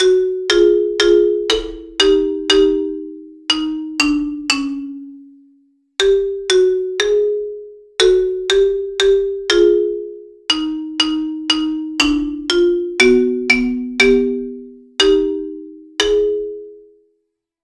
I have made music. uncomfortable music. I have discovered that I am very bad at making music that does not sound uncomfortable. Just like my love for bad color pallets, my love for off tone sounding musics comes to creation.
I... wanted to make something that sounded pretty.